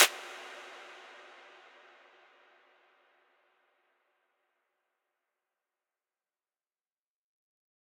menu_select_huge.ogg